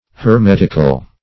Hermetic \Her*met"ic\, Hermetical \Her*met"ic*al\, a. [F.